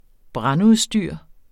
Udtale [ ˈbʁɑn- ]